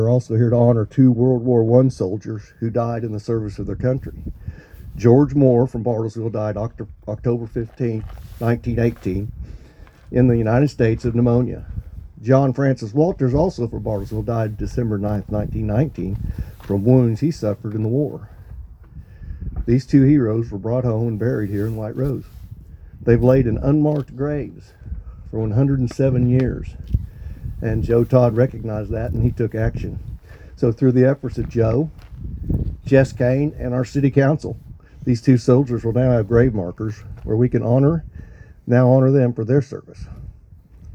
Bartlesville’s Wreaths Across America ceremony was held Saturday morning at White Rose Cemetery, honoring local veterans with a public remembrance event.
State Rep. John B. Kane addressed attendees, sharing the story of two World War I soldiers buried at White Rose who recently received long-overdue headstones.